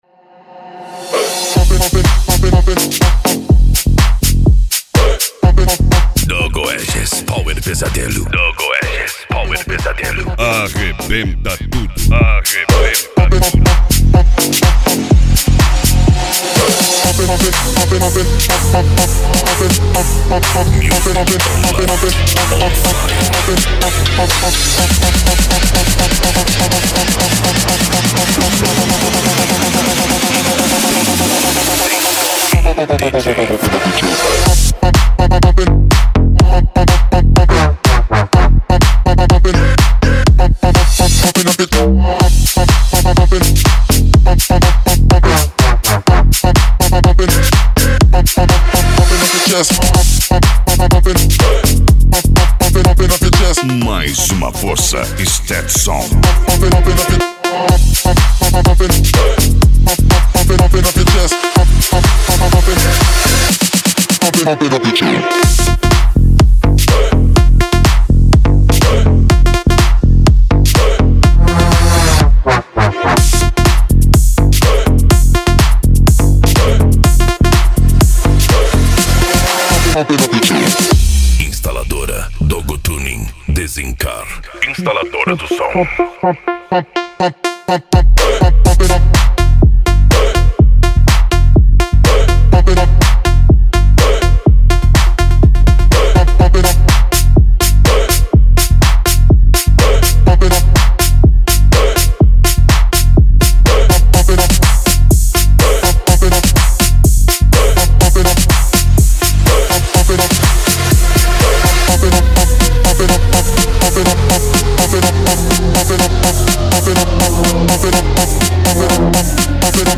Musica Electronica
Psy Trance
Remix
Techno Music
Trance Music